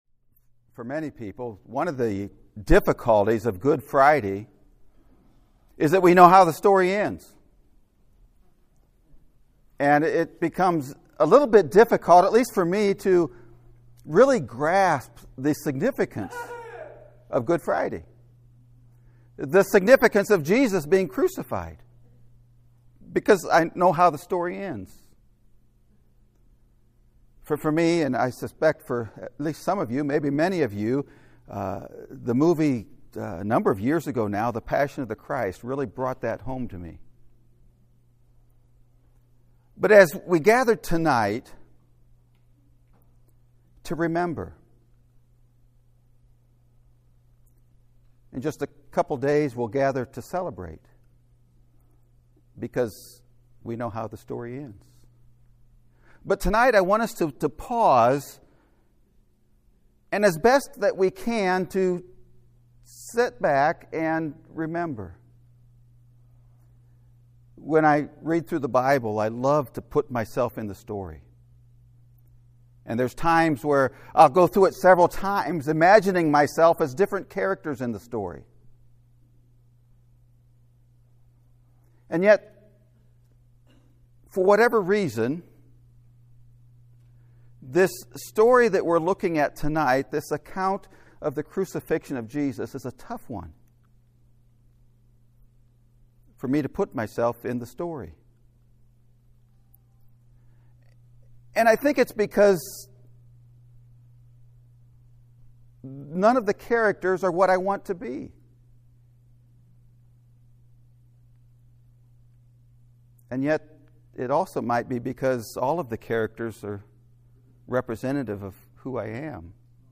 A TIME to Remember (Sermon Audio)